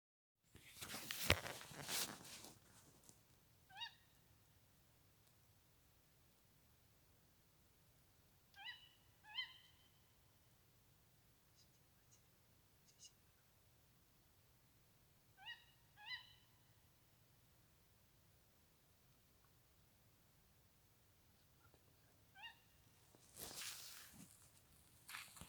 Birds -> Owls -> 1
Tawny Owl, Strix aluco
StatusPair observed in suitable nesting habitat in breeding season